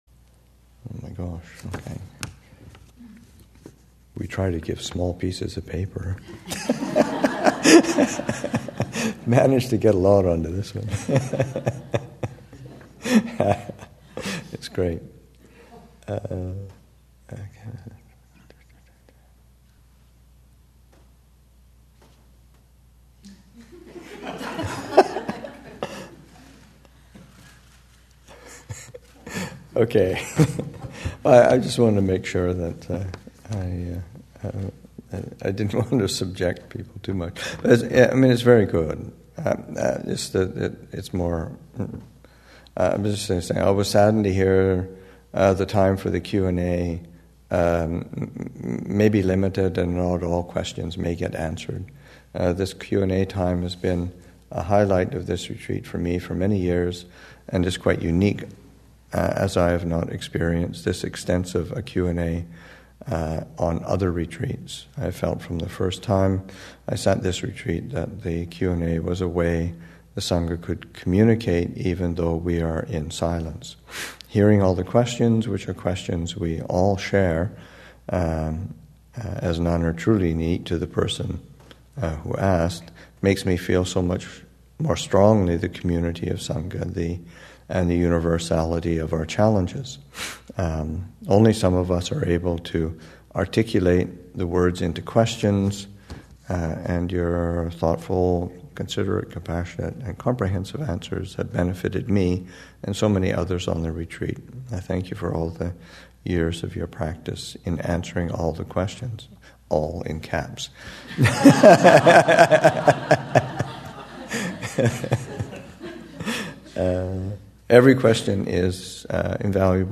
2015 Thanksgiving Monastic Retreat, Session 2 – Nov. 22, 2015